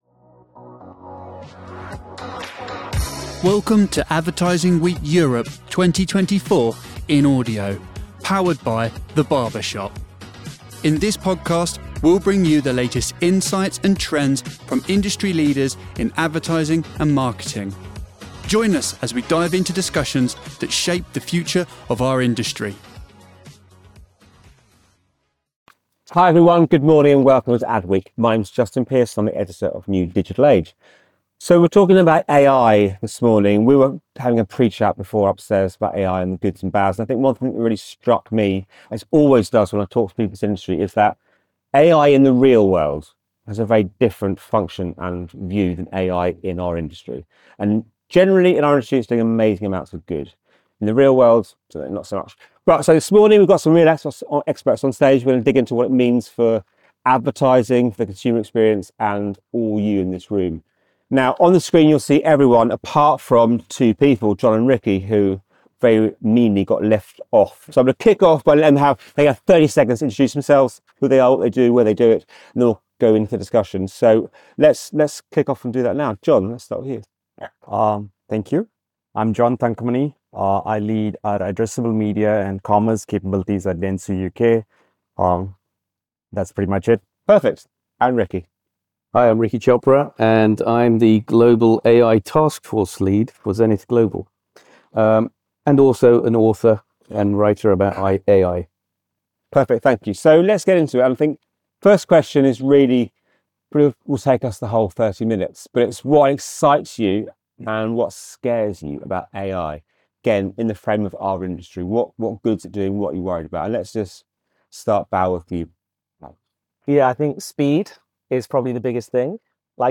Dive into the future of data-driven advertising with insights on how AI can optimize media strategy and maximize advertising investment. This panel, featuring leaders from Addressable Media, Zenith, NewDigitalAge, DoubleVerify, Wavemaker, and Omnicom Media Group, discusses the use of AI to analyze vast data sets, improve media performance, and achieve higher ROI.